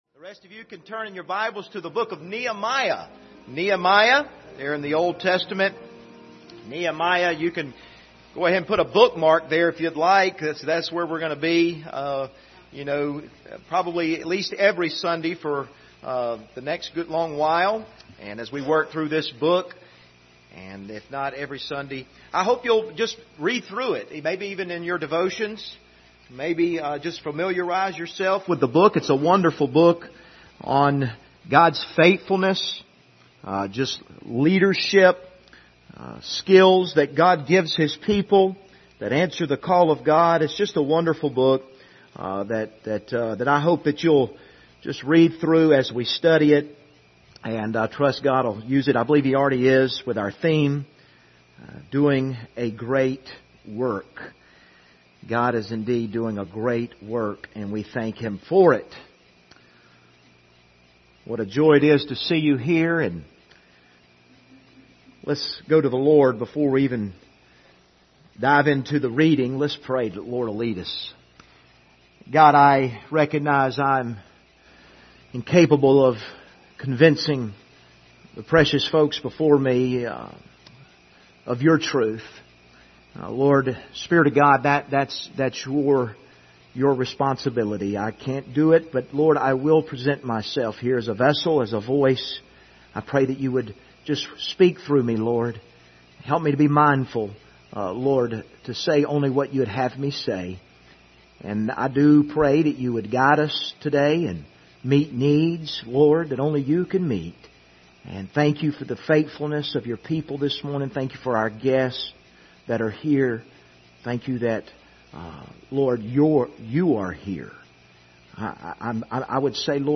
Doing a Great Work Passage: Nehemiah 1:1-5 Service Type: Sunday Morning View the video on Facebook « God Help Us!